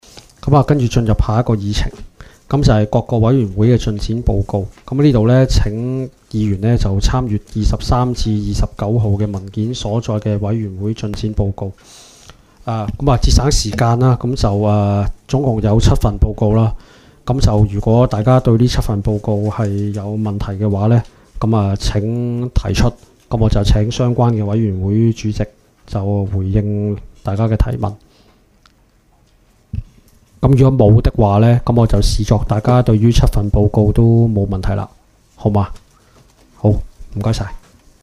区议会大会的录音记录
地点: 元朗桥乐坊2号元朗政府合署十三楼会议厅